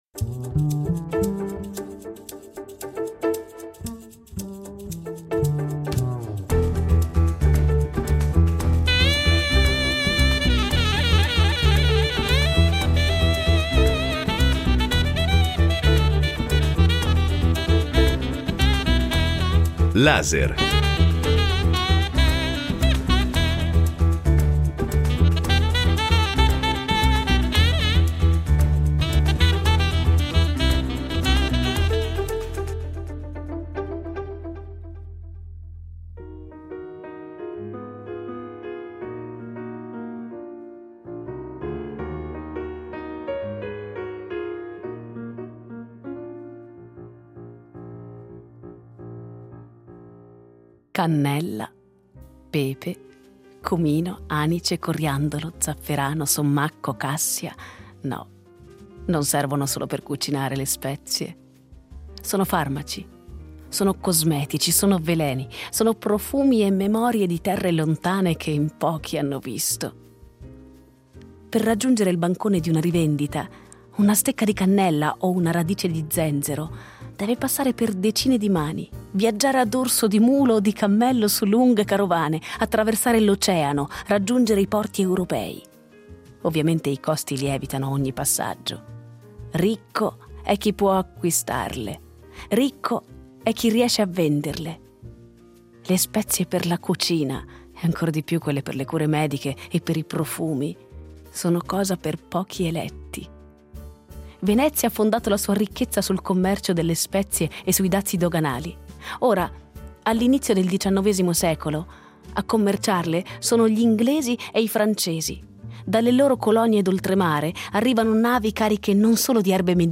Incontro con Stefania Auci